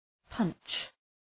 Προφορά
{pʌntʃ}